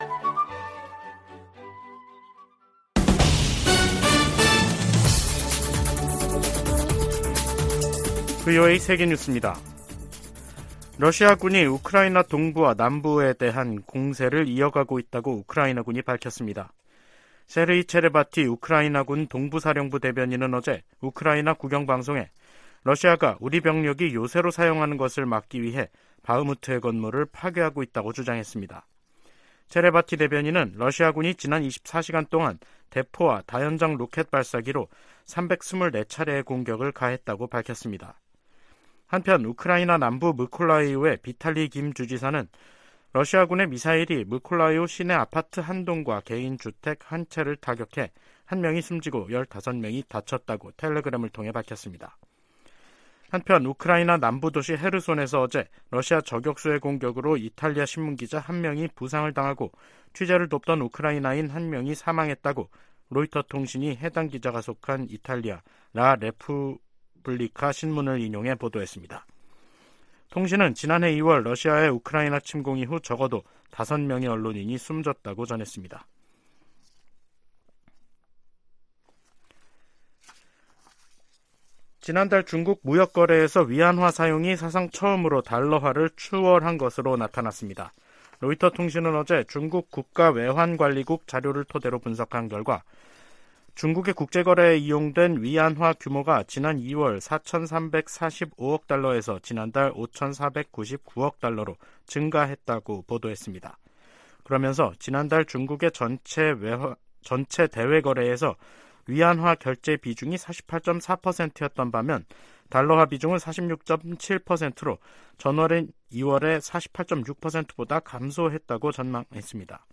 VOA 한국어 간판 뉴스 프로그램 '뉴스 투데이', 2023년 4월 27일 2부 방송입니다. 조 바이든 미국 대통령과 윤석열 한국 대통령이 26일 백악관 회담에서 '워싱턴 선언'을 채택하고, 미한 핵협의그룹을 창설하기로 했습니다. 전문가들은 이번 정상회담에서 양국 관계가 '글로벌 포괄적 전략동맹'으로 격상을 확인했다고 평가했습니다. 한국 내에선 워싱턴 선언이 북 핵 위협 고도화에 맞서 미국의 확장억제 공약을 보다 구체화했다는 평가가 나오고 있습니다.